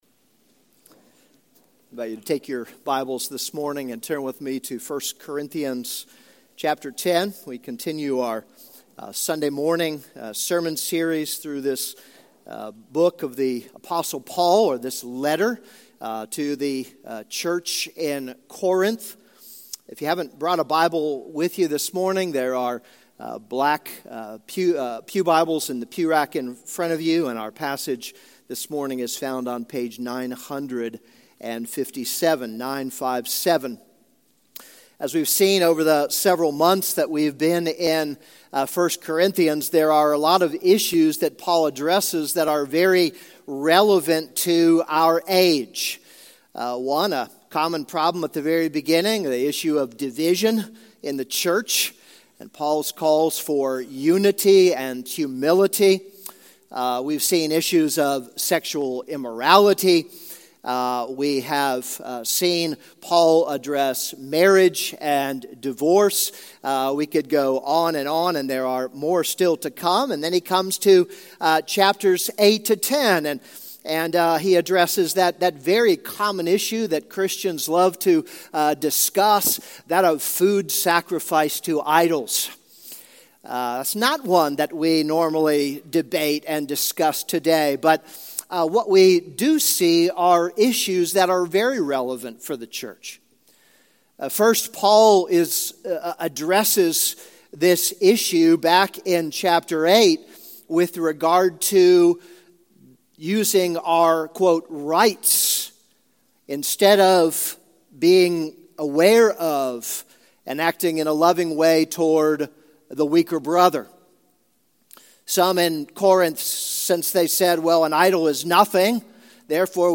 This is a sermon on 1 Corinthians 10:14-22.